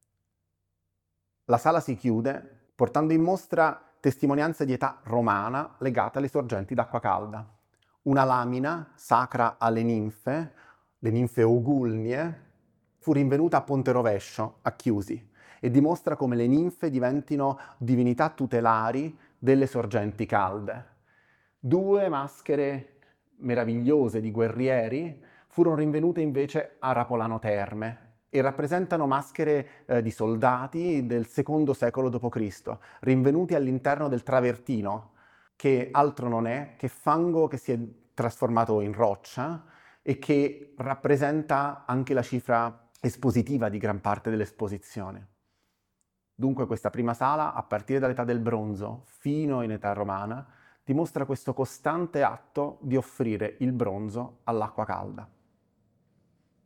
L’audioguida dedicata alla mostra “Gli Dei ritornano. I Bronzi di San Casciano”